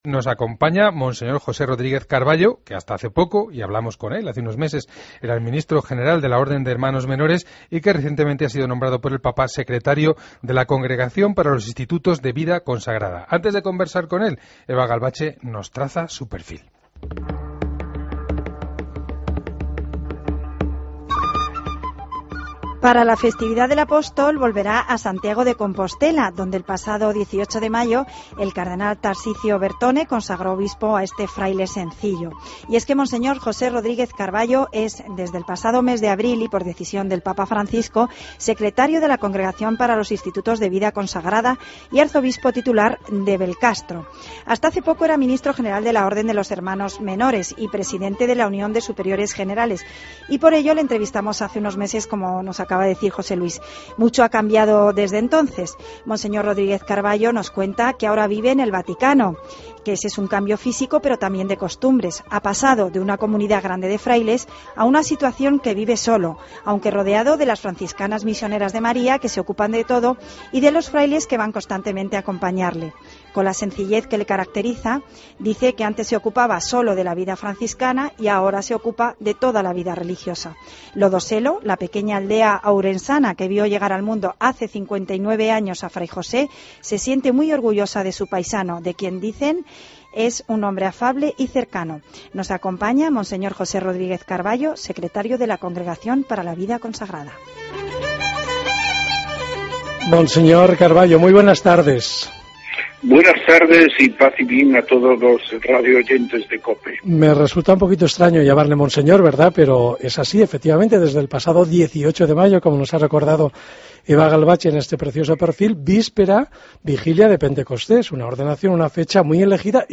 Escucha la entrevista a monseñor Rodríguez Carballo